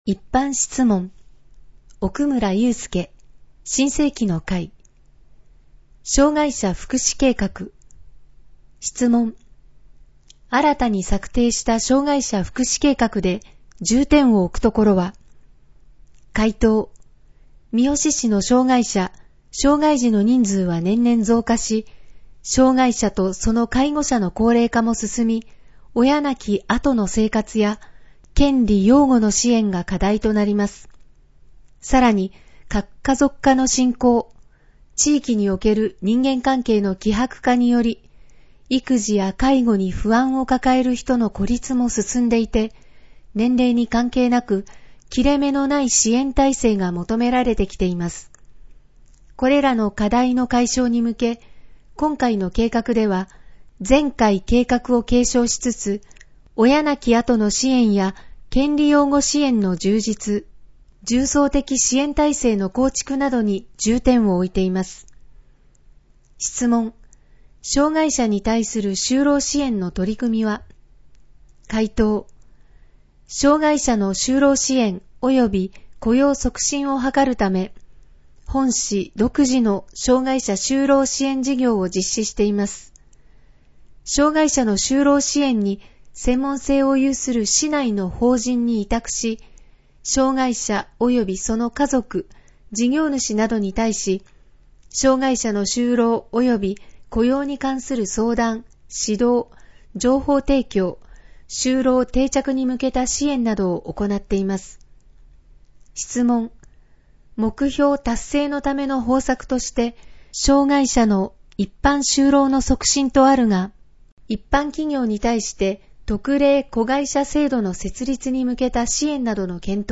『声の議会広報』は、「みよし議会だより きずな」を音声情報にしたもので、平成29年6月15日発行の第110号からボランティア団体「やまびのこ会」の協力によりサービス提供をはじめました。